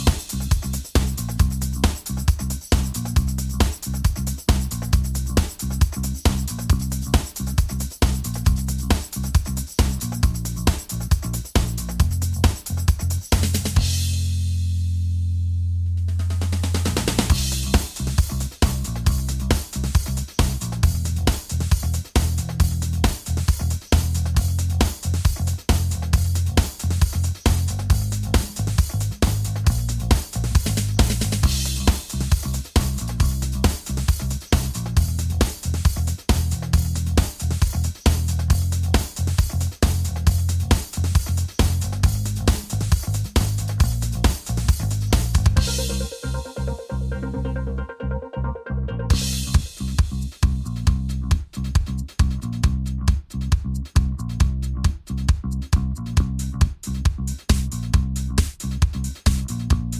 Minus Main Guitars For Guitarists 4:31 Buy £1.50